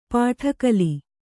♪ pāṭha kali